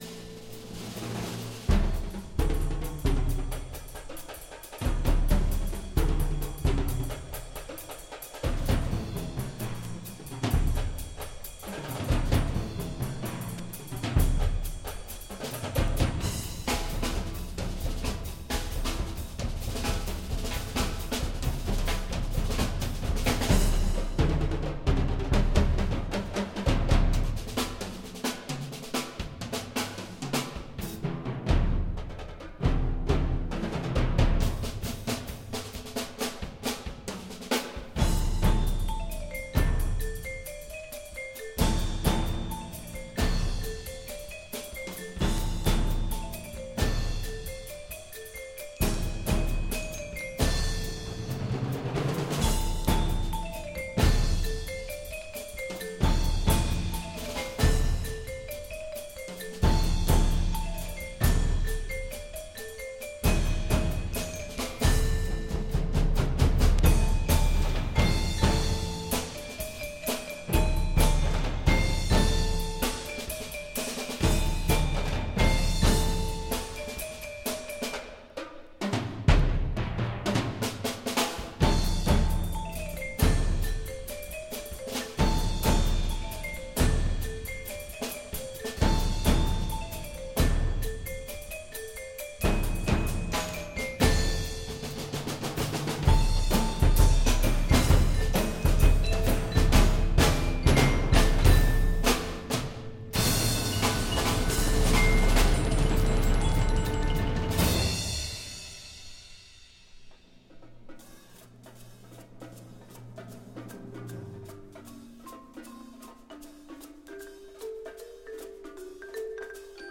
Ethnic percussion instruments from Latin America, Africa